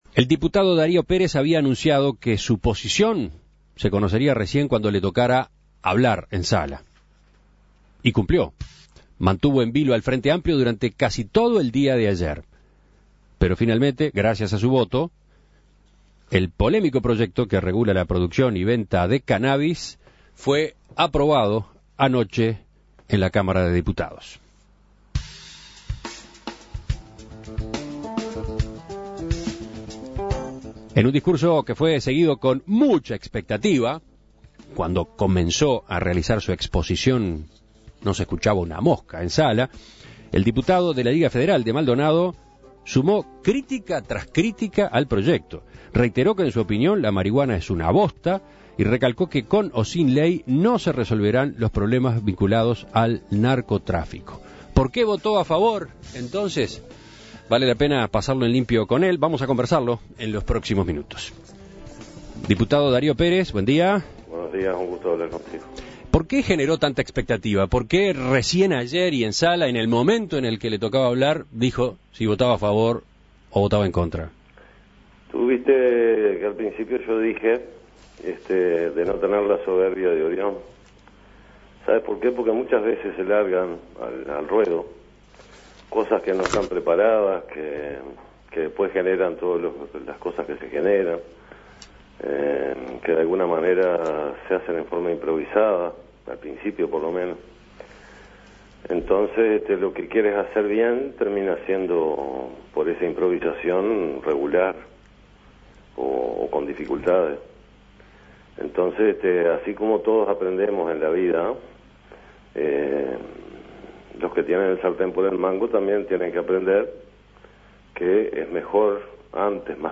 Escuche la entrevista a Darío Pérez